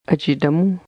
Some long vowels are pronounced with air flowing through the nose.